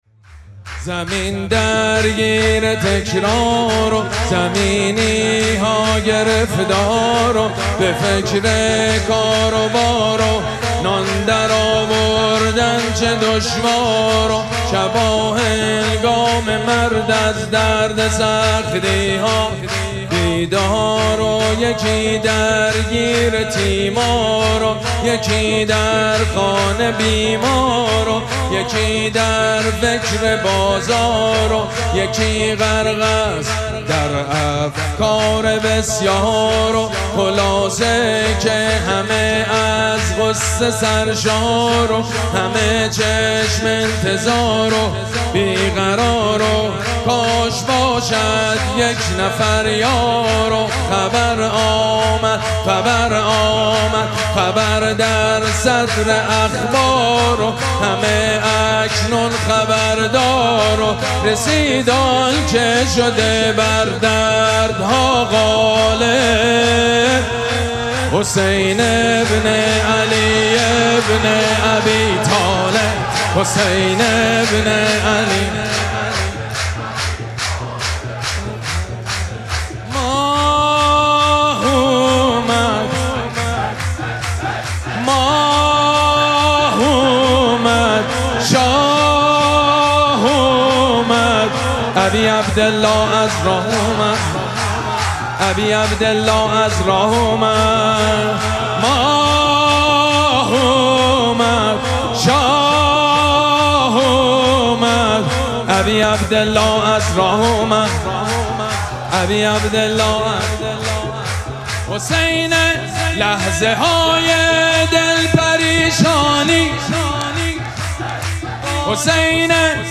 شب اول مراسم جشن ولادت سرداران کربلا
حسینیه ریحانه الحسین سلام الله علیها
شور
مداح
حاج سید مجید بنی فاطمه